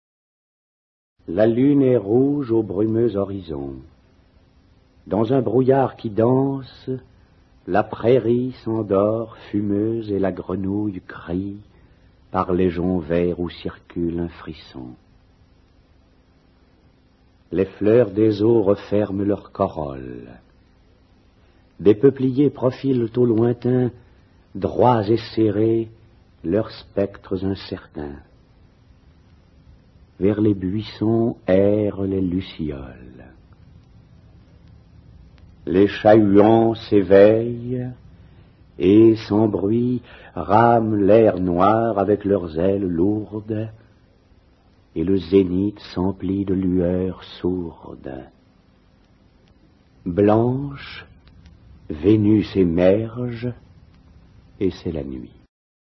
dit par Jean DESAILLY